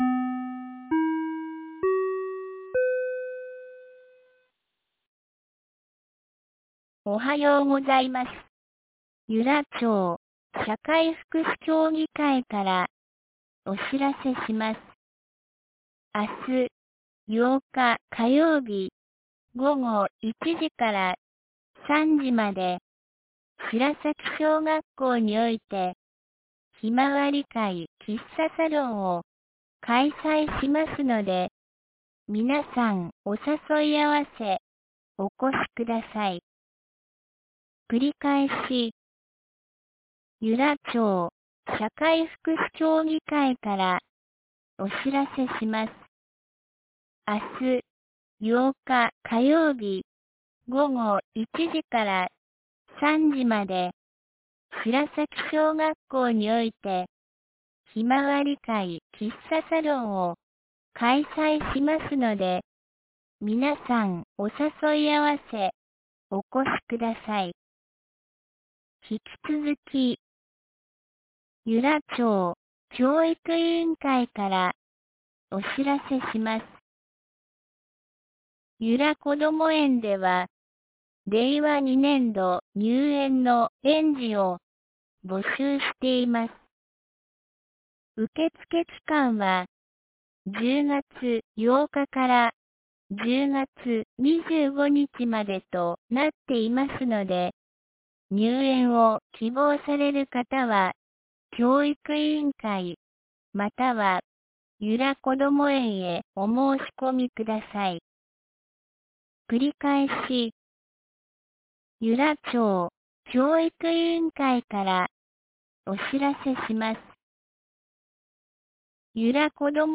2019年10月07日 07時53分に、由良町から全地区へ放送がありました。
放送音声